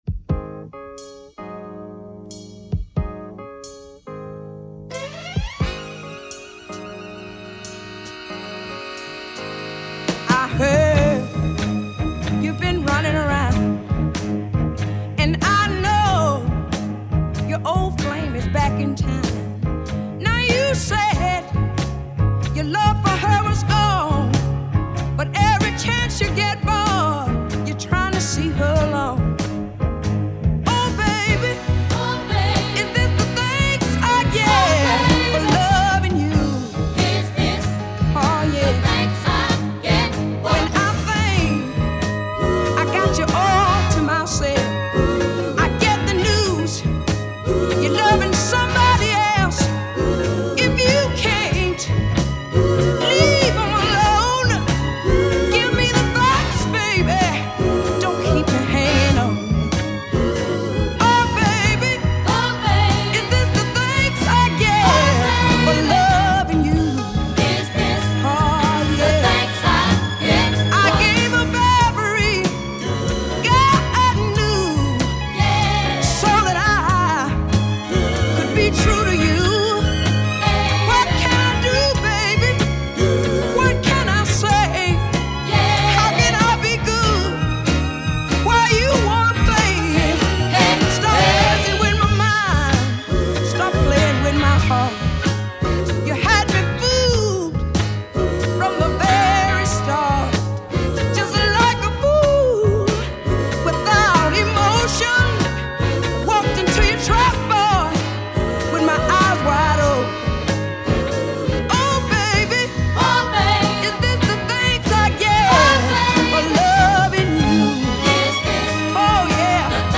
Tag: southern soul